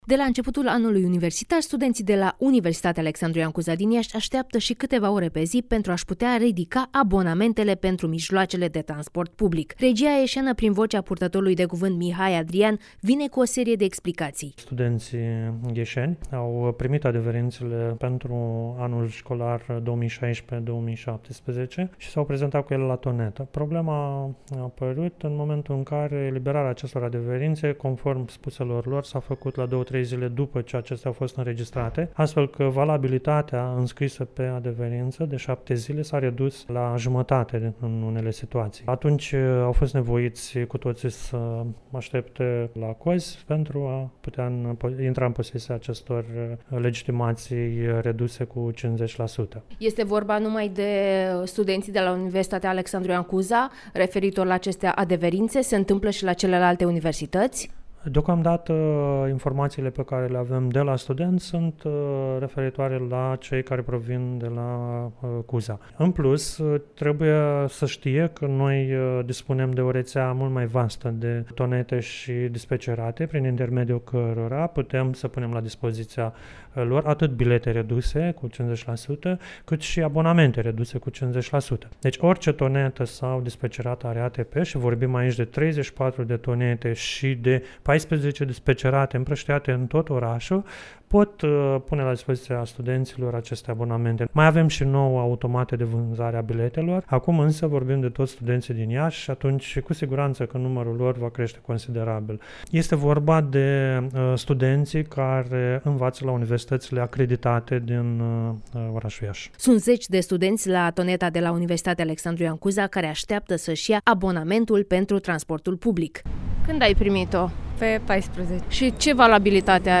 radioreportaj